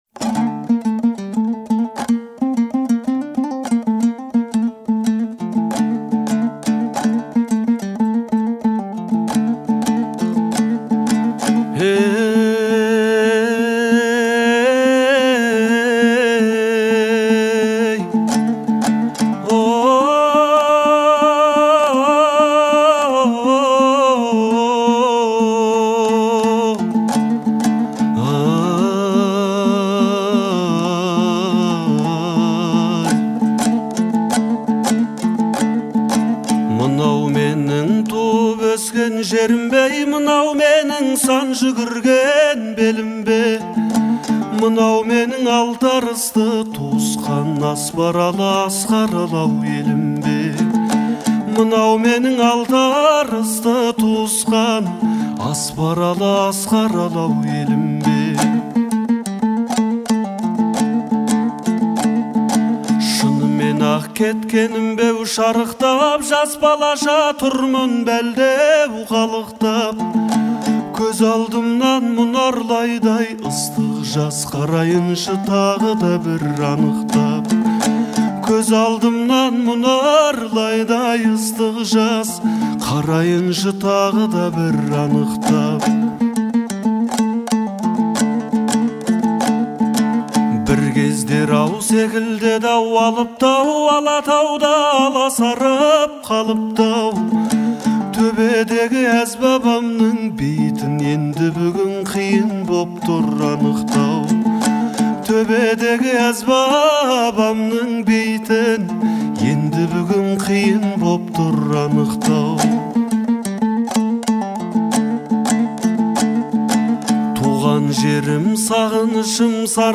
это трогательная песня в жанре казахской народной музыки
В ней звучит глубокая ностальгия и любовь к родной земле.
Мелодия нежная и меланхоличная